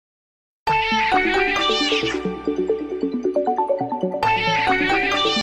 Kitten Meow Ringtone Phone 🐱😁 sound effects free download